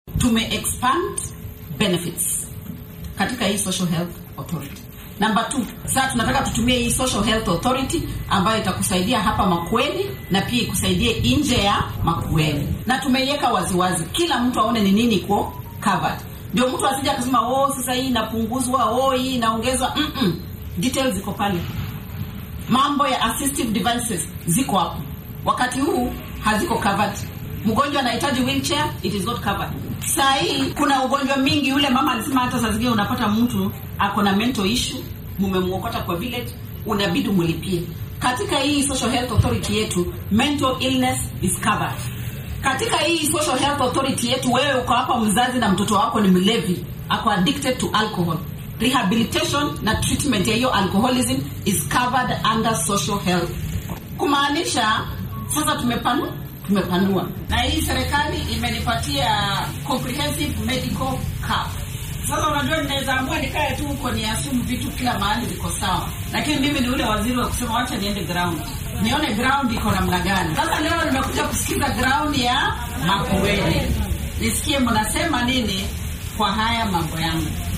Wasiiradda wasaaradda caafimaadka ee dalka Susan Wafula Nakhumicha ayaa xilli ay ku sugnayd ismaamulka Makueni waxay sharraxaad ka bixisay caymiska caafimaadka bulshada ee SHIF ee lagu beddelay nidaamkii hore ee NHIF. Waxay hoosta ka xarriiqday in caymiskan uu daboolayo baahiyo dheeraad oo dhanka daryeelka ah sida caafimaadka maskaxeed, daaweynta dadka qabatimay isticmaalka daroogada, agabka dadka naafada ah iyo waxyaabo kale.